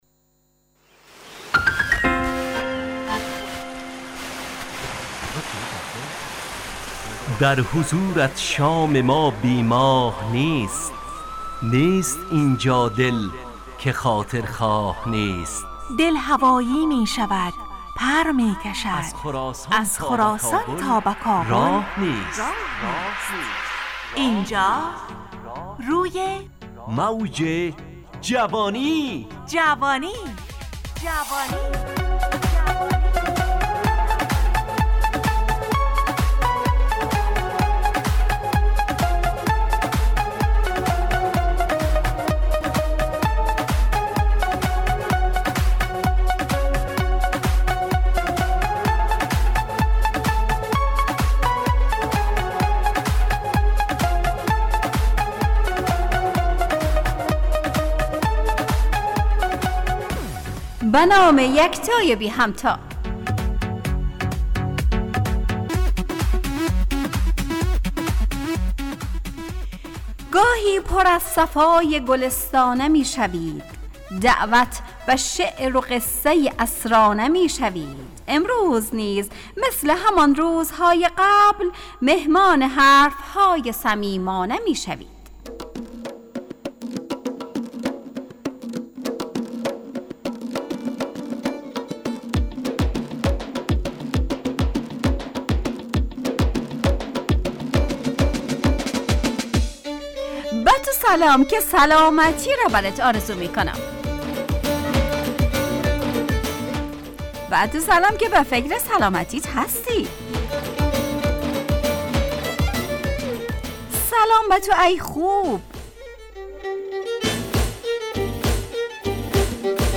روی موج جوانی، برنامه شادو عصرانه رادیودری.
همراه با ترانه و موسیقی .